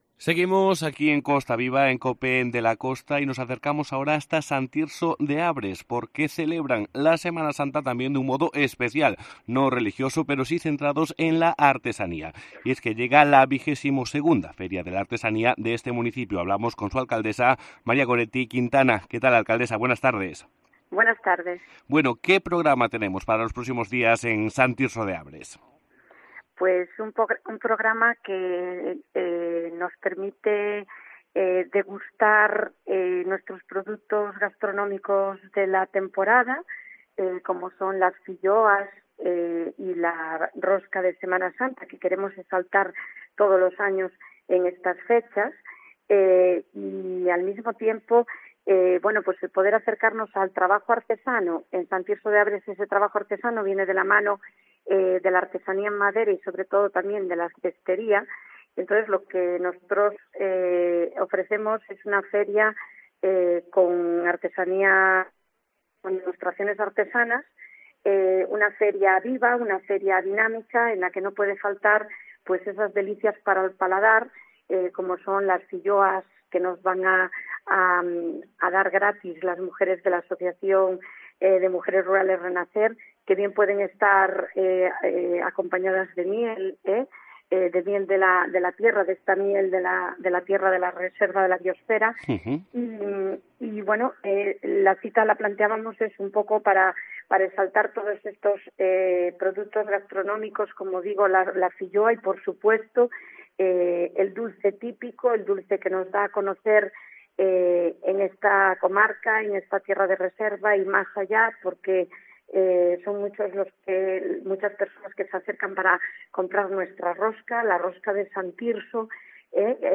Entrevista con María Goretti Quintana, alcaldesa de San Tirso de Abres